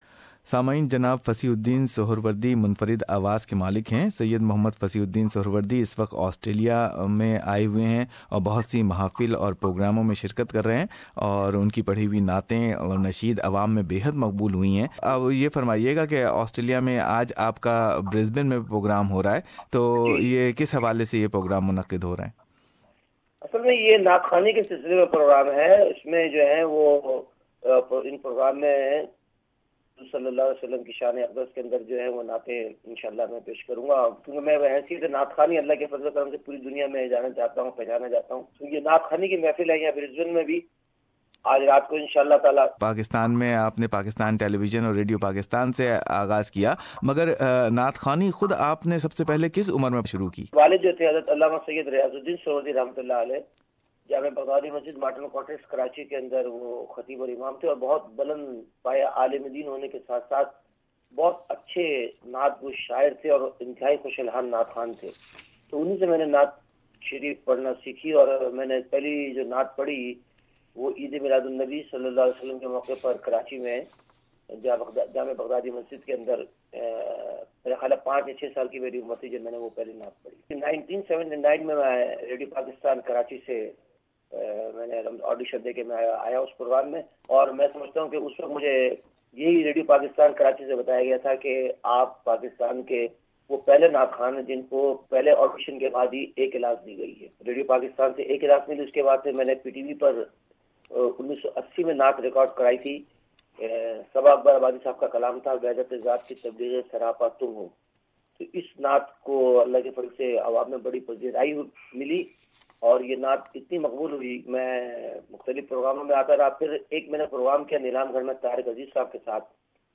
Syed Fasih Uddin Suherwardi is a leading nasheed reciter and Naat Khawan from Pakistan and currently visiting Australia.
Listen his talk, glimpse of his famous Naat and his Australian schedule.